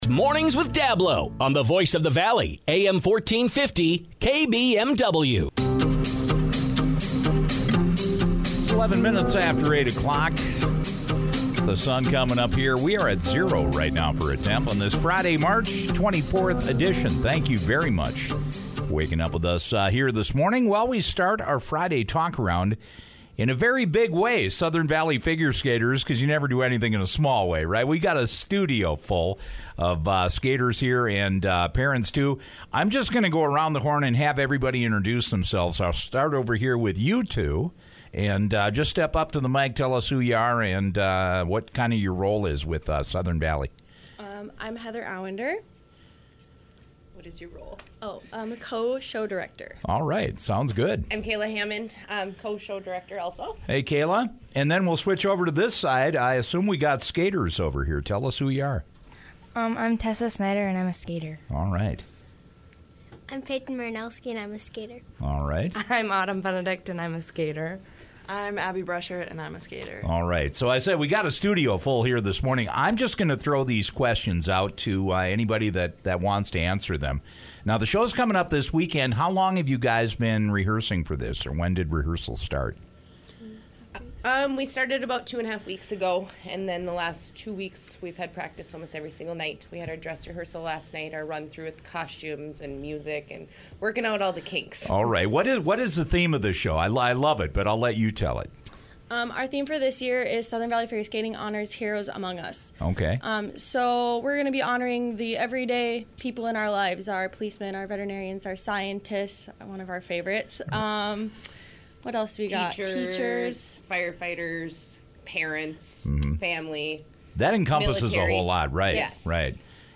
The Southern Valley Figure Skaters take to the ice this Saturday and Sunday at the Stern Sports Arena. The group stopped by the KBMW Morning Show on Friday to talk about the weekend event.